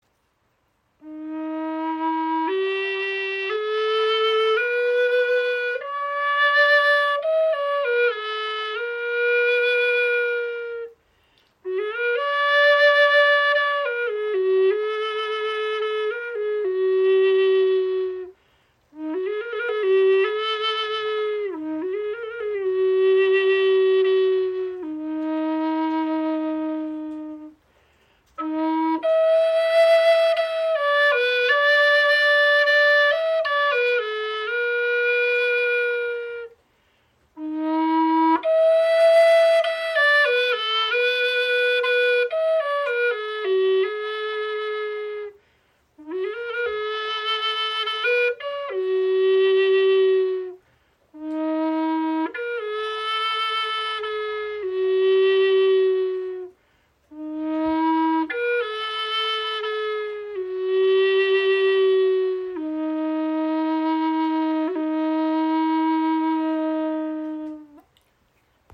Gebetsflöte in E – 432 Hz | Spirit of Nature | Akazienholz, 61 cm
• Icon 69 cm, fünf Grifflöcher – handlich für Rechtshänder
Gestimmt in E auf 432 Hz, ihr angenehmer, tiefer Klang aktiviert Dein Solarplexus-Chakra und harmonisiert Körper, Geist und Herz.
Ihr klarer, erhebender Ton lädt zu Meditation, Gebet, Klangreisen oder musikalischer Improvisation ein.